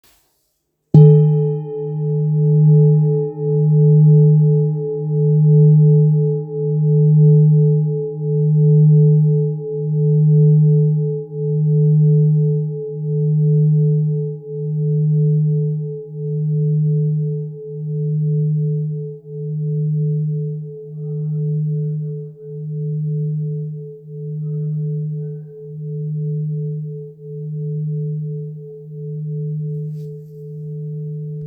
Kopre Singing Bowl, Buddhist Hand Beaten, Antique Finishing
Material Seven Bronze Metal